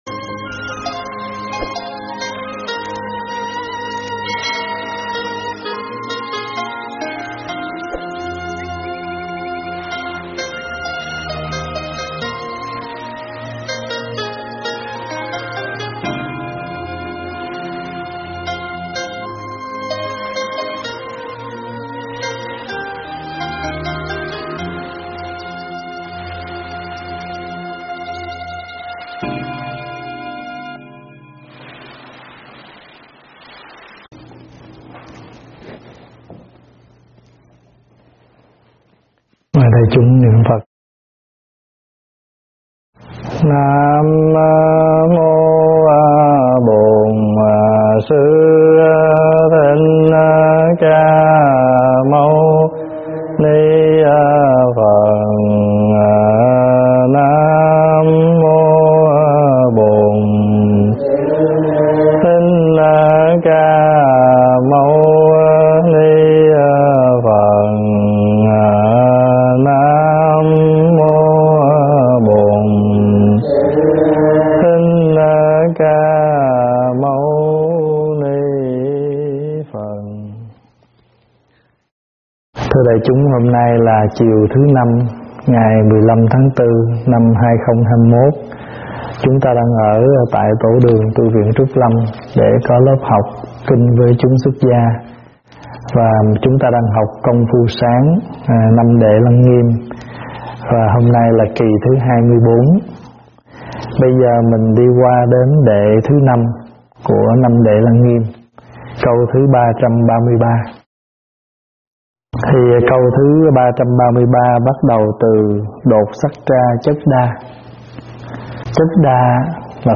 pháp thoại
giảng tại Tv.Trúc Lâm